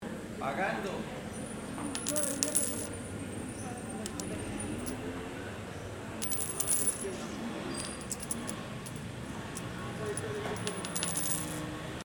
Registro sonoro del proceso de producción de café en Trujillo, Valle del Cauca. Fase de pagar el café.